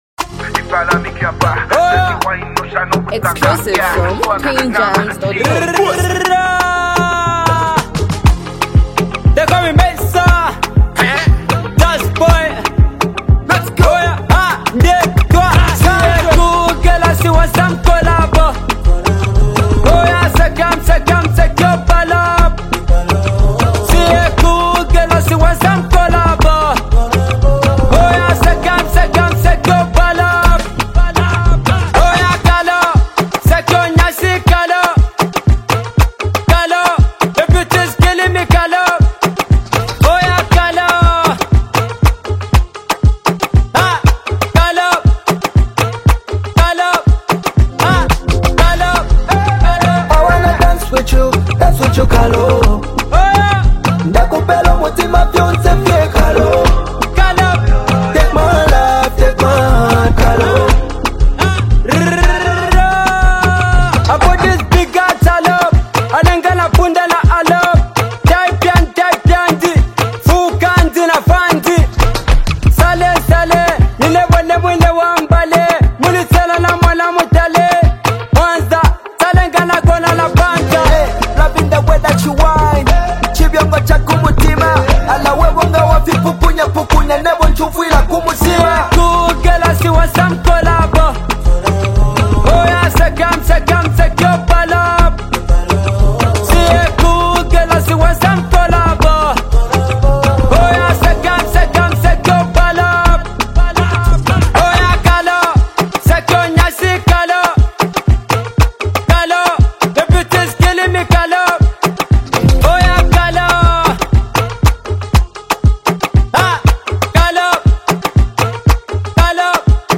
blends Afro-pop with a touch of Zambian street flavor
lays down a melodic and catchy hook, setting the tone